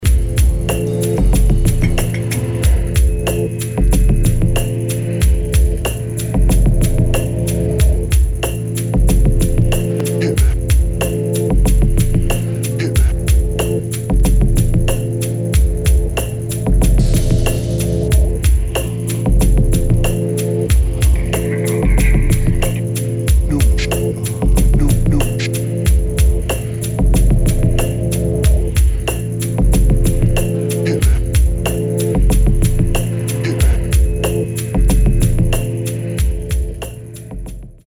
[ DOWNBEAT / BASS / EXPERIMENTAL ]